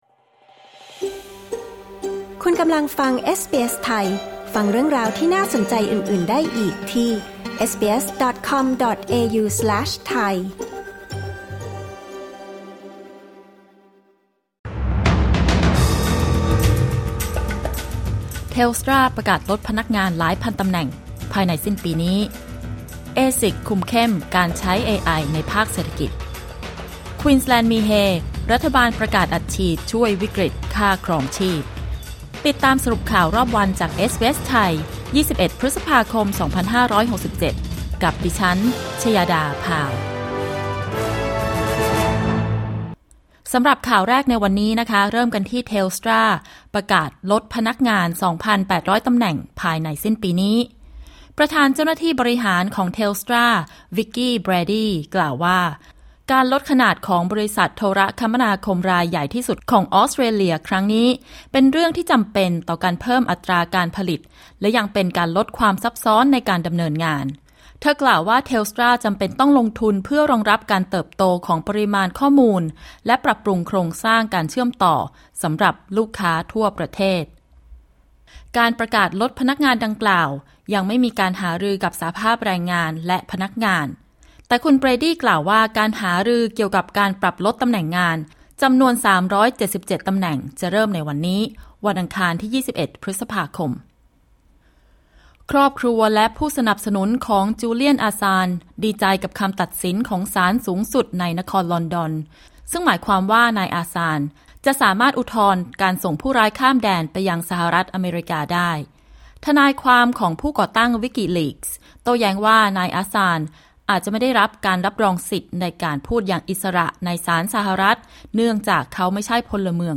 คลิก ▶ ด้านบนเพื่อฟังรายงานข่าว